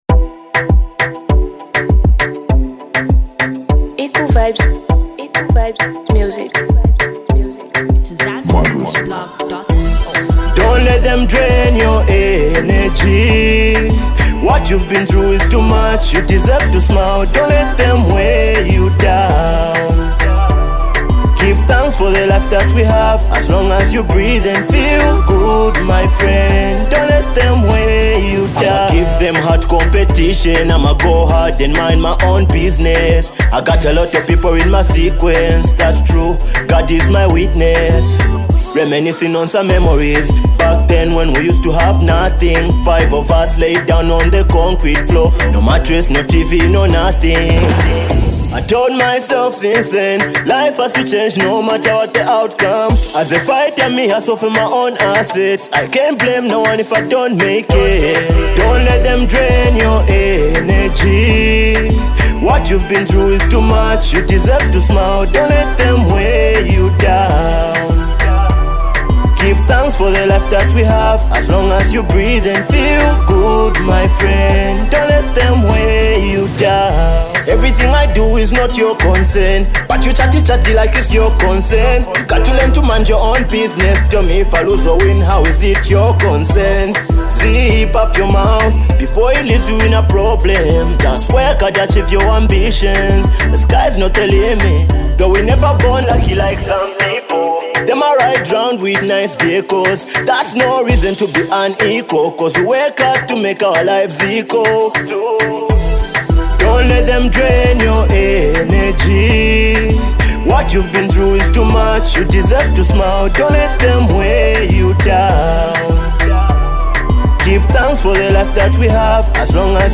an energetic, mood-lifting anthem
lighter, celebratory energy